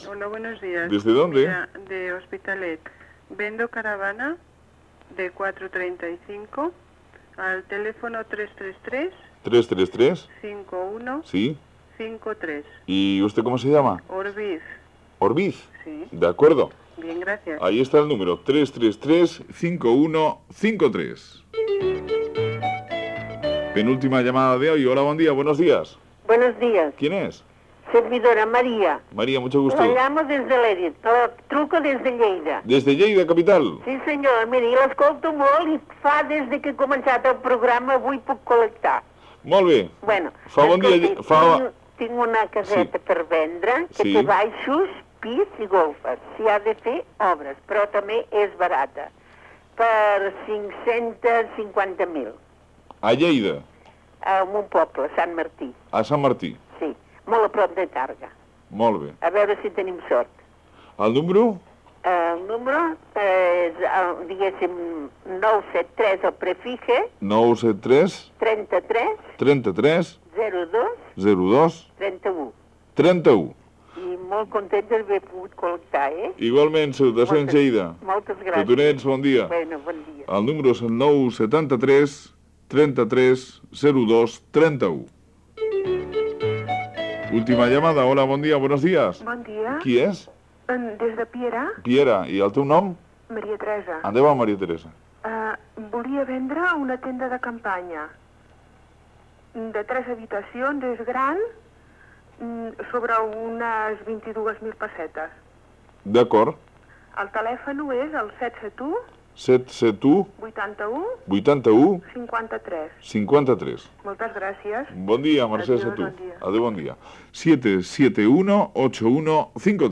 Final de la secció "Area de servicio" on els oients compren, venen, intercanvien o donen ofertes laborals, indicatiu musical de la Cadena Catalana
Entreteniment